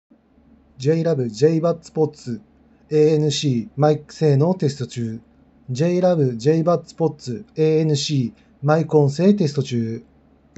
声のこもりも少なくちゃんと使える性能
マイク性能はいい
✅「JLab JBuds Pods ANC」マイク性能
イヤーカフ型の「JLab Flex Open Earbuds」のマイク性能はハッキリってひどかったですが、「JLab JBuds Pods ANC」はしっかりしている。